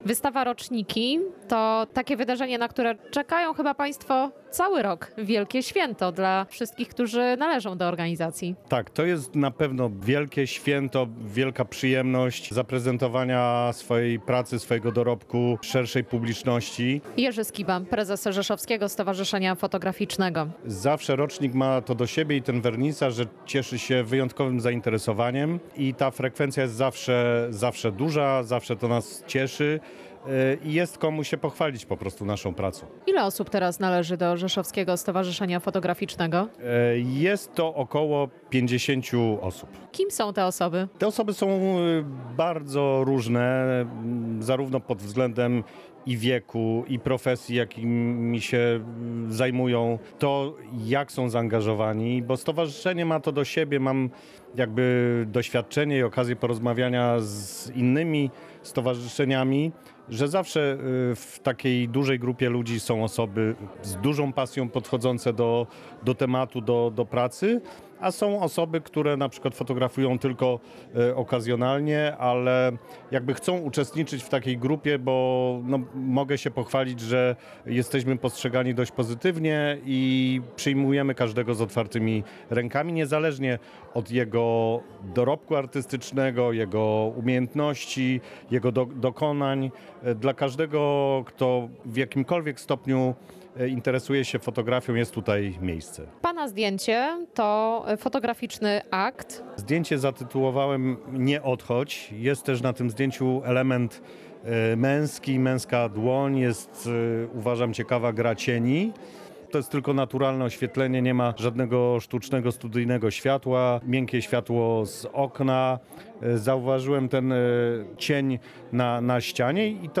Relacje reporterskie • Najciekawsze fotografie minionego roku można oglądać w Rzeszowie.